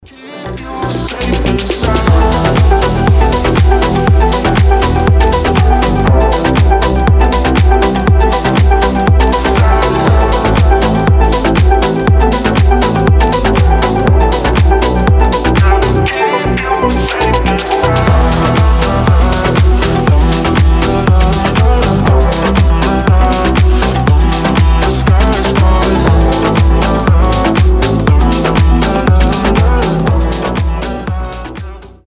• Качество: 320, Stereo
deep house
мелодичные